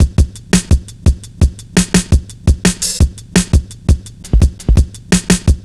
Index of /90_sSampleCDs/Zero-G - Total Drum Bass/Drumloops - 3/track 62 (170bpm)